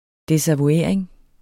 Udtale [ desavuˈeɐ̯ˀeŋ ] eller [ ˈdesavuˌeɐ̯ˀeŋ ]